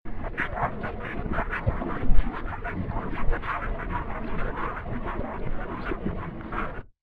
Halt_Whisper_at_1st.wav